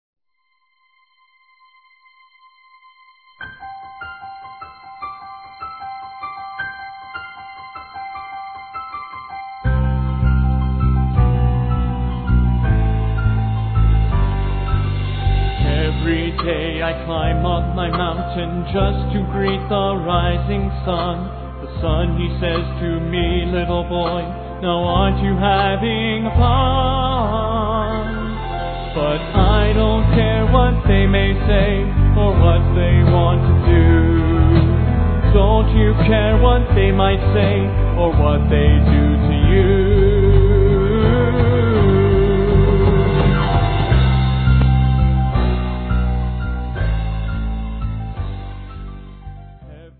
(solo)